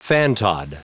Prononciation du mot fantod en anglais (fichier audio)
Prononciation du mot : fantod